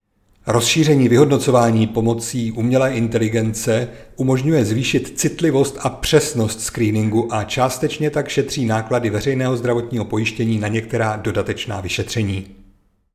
Audio k tiskové zprávě namluvené